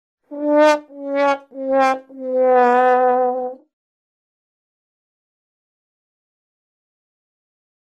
Грустный тромбон